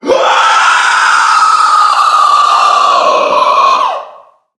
NPC_Creatures_Vocalisations_Puppet#3 (hunt_03).wav